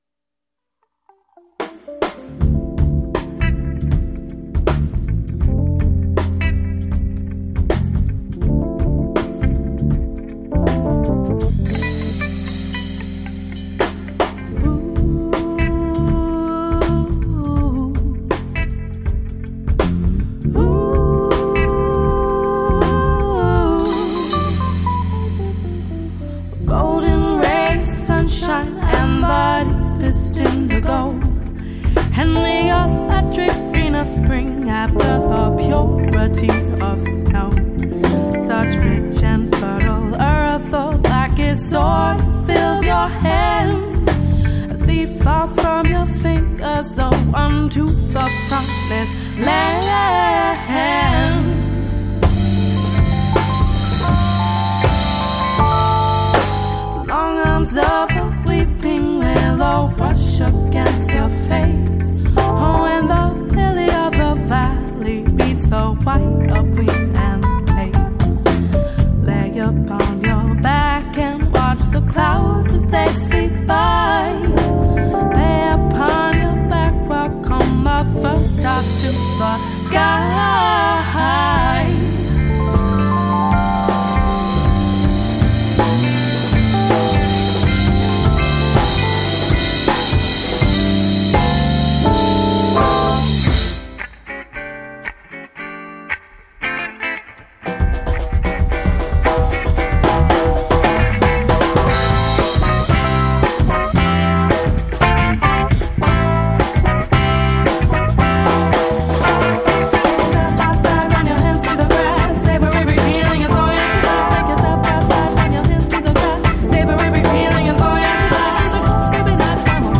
Genre Folk Rock